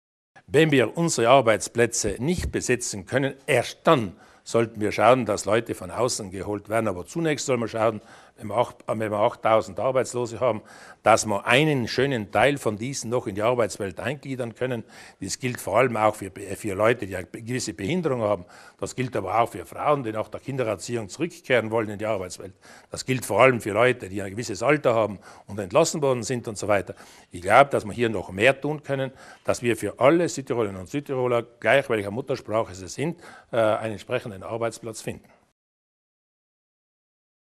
Landeshauptmann Durnwalder zum Thema Arbeitsmarkt